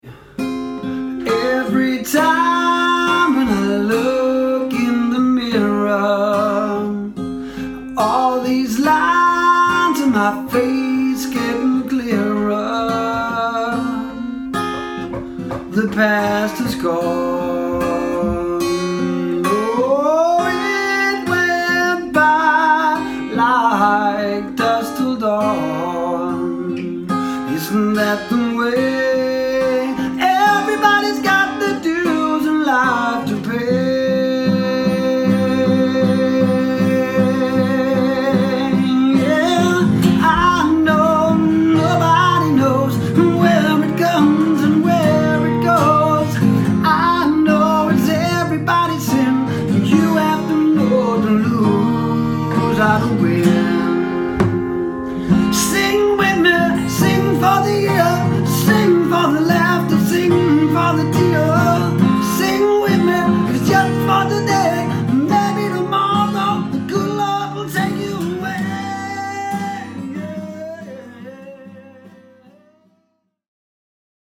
Jeg spiller guitar og synger, og det er det bedste jeg ved.